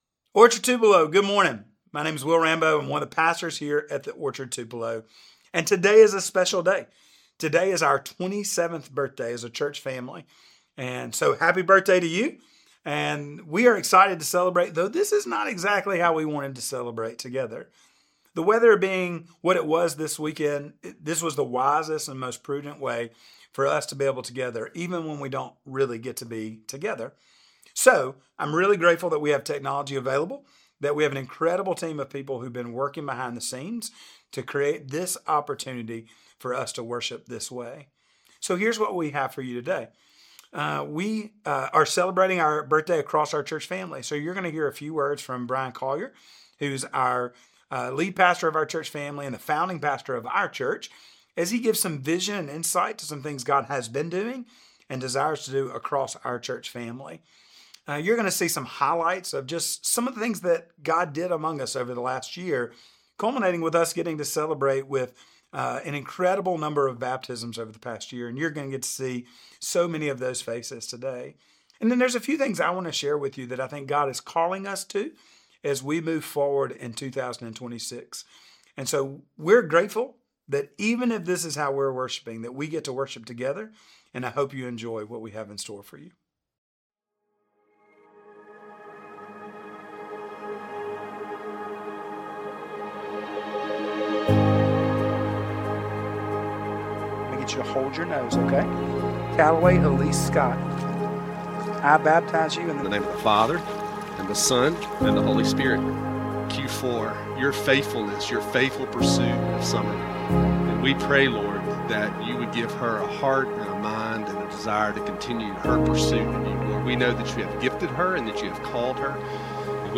Sermon Series: Orchard's 27th Birthday